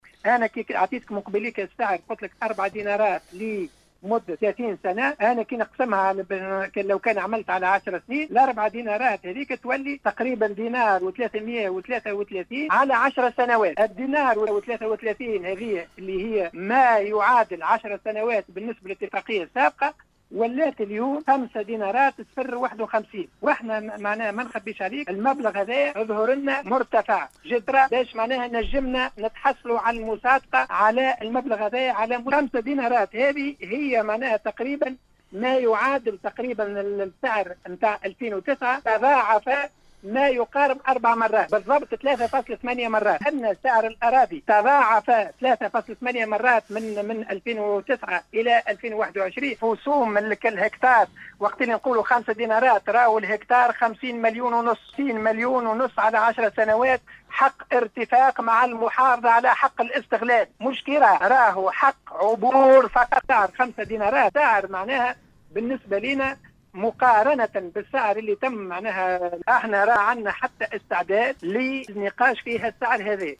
تصريح لاذاعة السيليوم اف ام